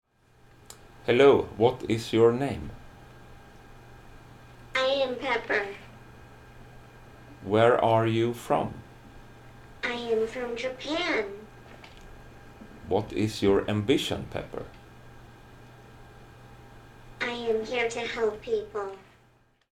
These recordings of Pepper includes: conversation with the sound recorder; some tricks Pepper can do and start up and shut down sequences.
• Social humanoid robot